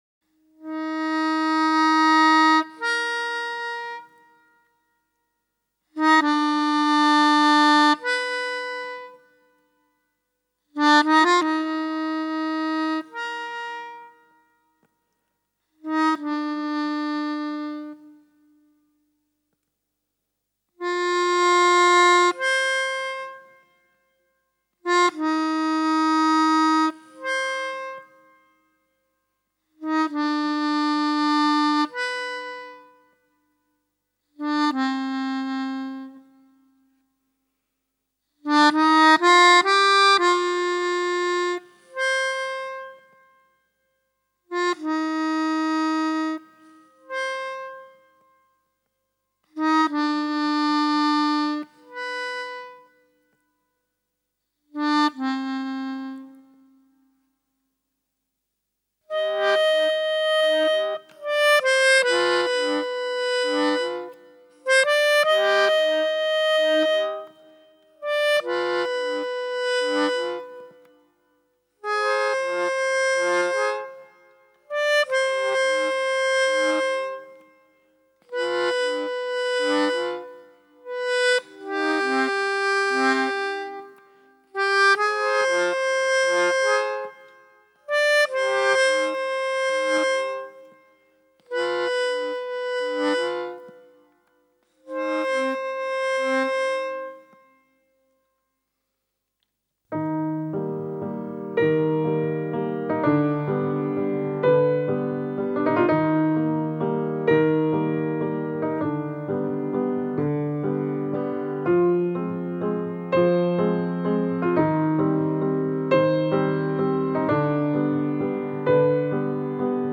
Genre: World Music, Alternative, Neo-Classical, Neo-Folk